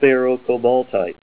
Help on Name Pronunciation: Name Pronunciation: Sphaerocobaltite + Pronunciation
Say SPHAEROCOBALTITE Help on Synonym: Synonym: Cobaltocalcite   ICSD 61066   PDF 11-692